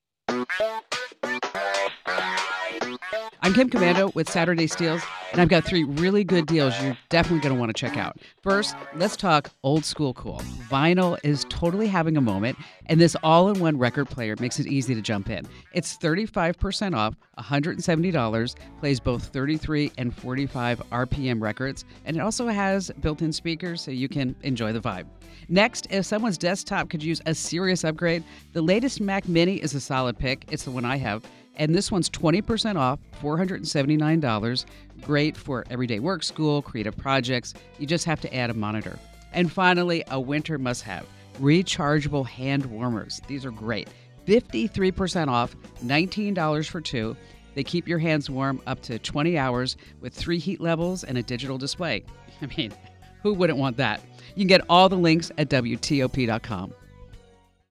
Kim Komando has three more deals including a must-have for winter.